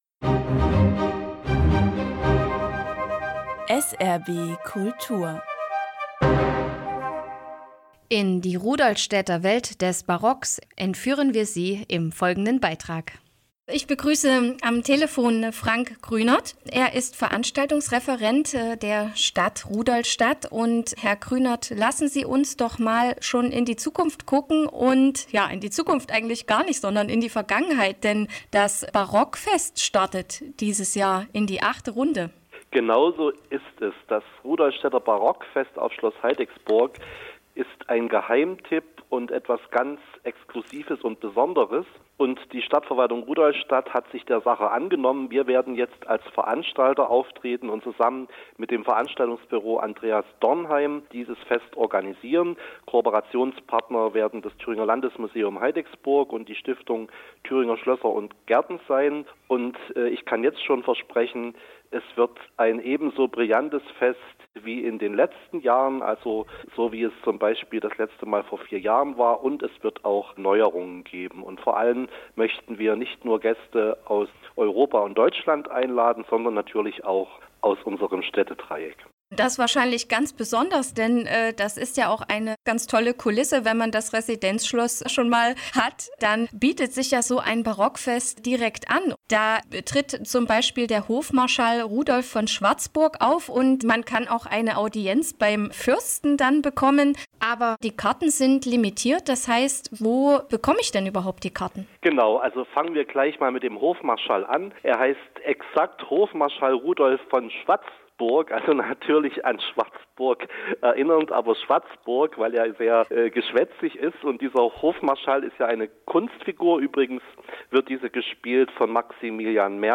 Radio-SRB-Interview zum 8. Barockfest